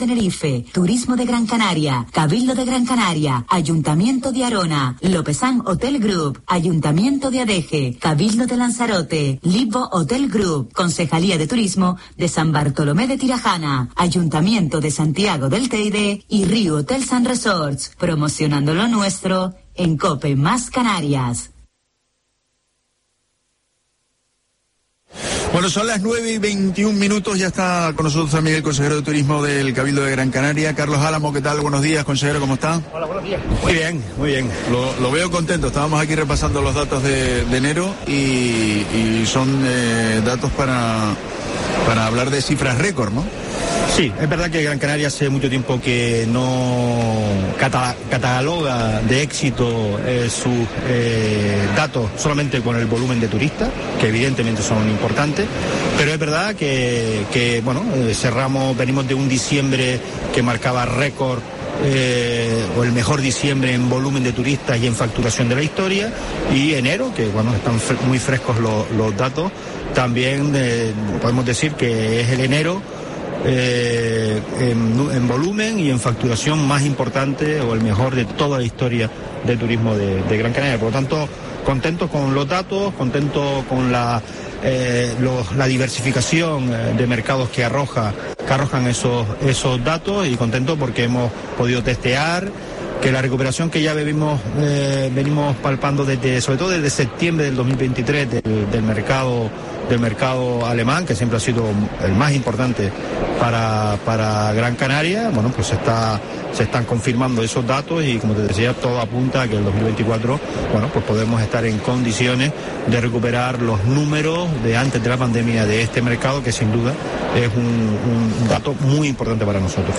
Entrevista a Carlos Álamo, consejero de Turismo del Cabildo de Gran Canaria, en la ITB de Berlín